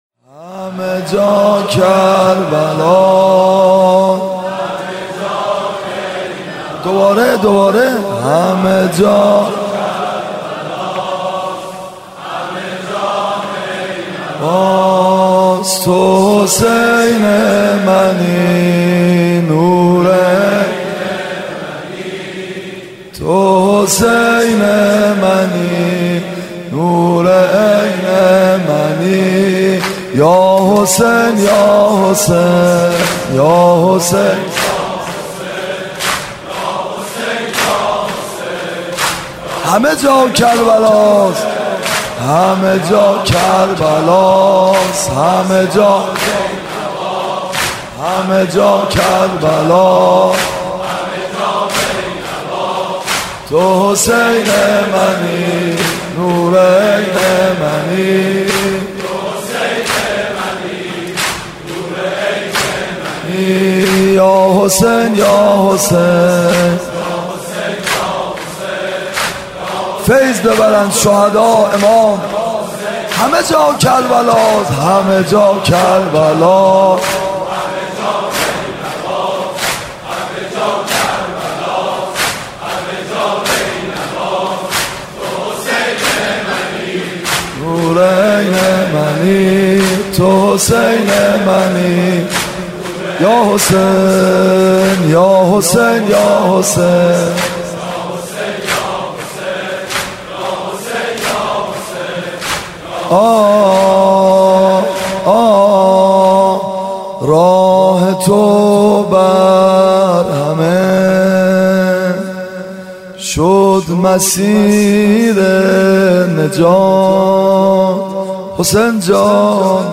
حاج میثم مطیعی
محرم 95
ميثم مطيعي محرم 95 نوحه